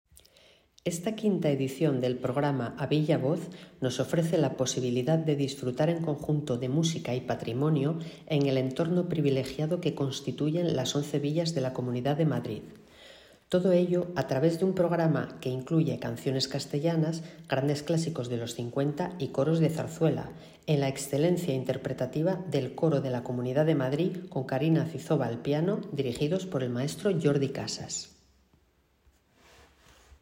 Audios con las declaraciones